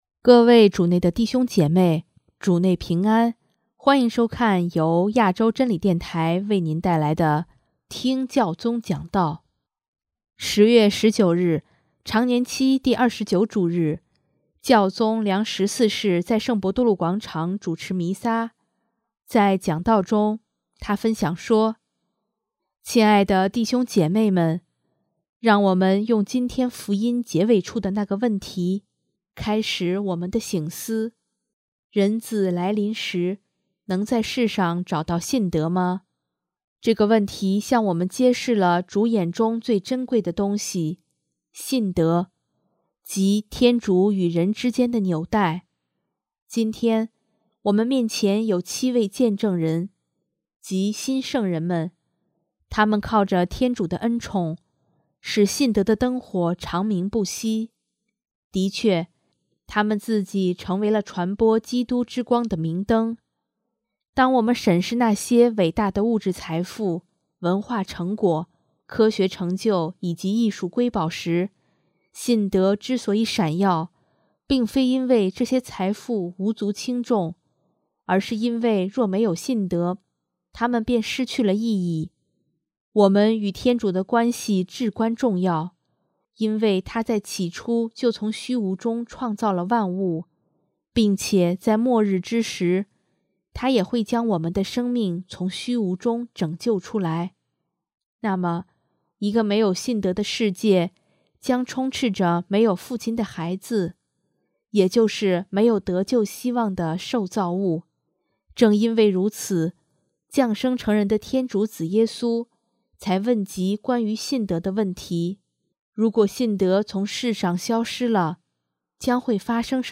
首页 / 新闻/ 听教宗讲道
10月19日，常年期第二十九主日，教宗良十四世在圣伯多禄广场主持弥撒，在讲道中，他分享说：